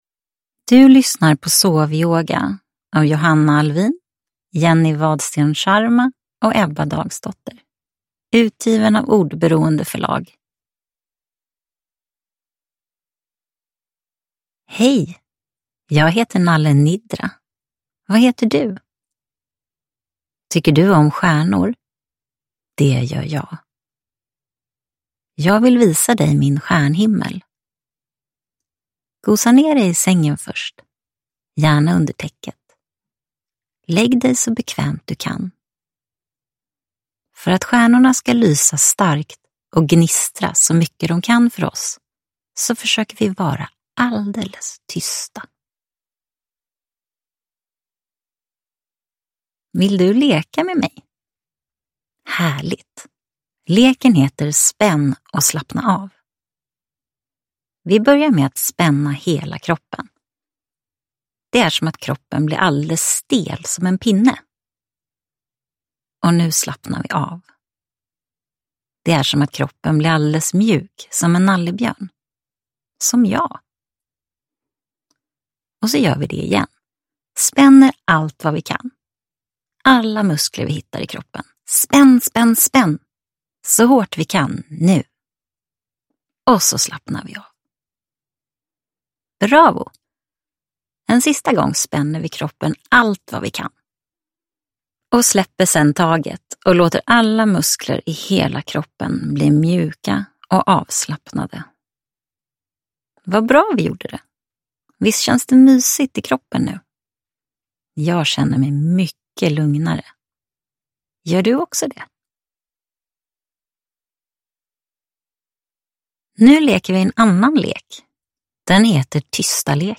Ljudbok
Boken är inläst av alla tre författare.